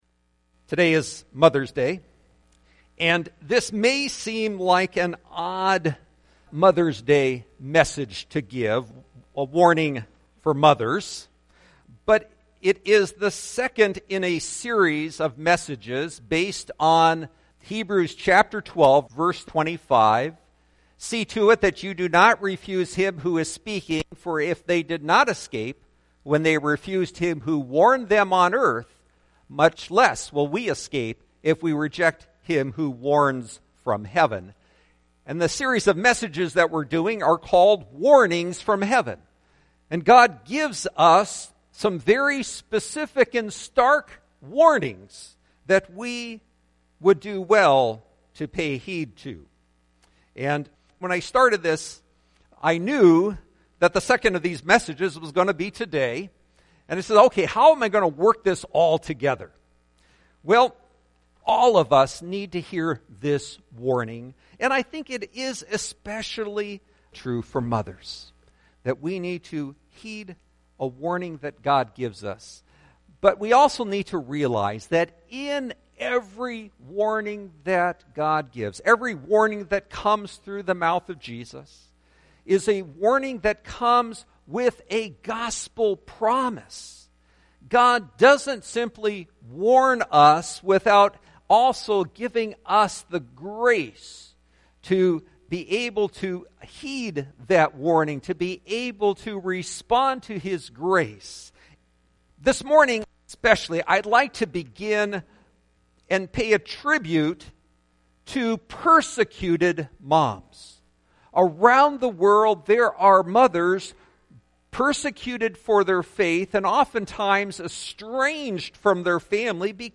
Warnings From Heaven Listen To Sermon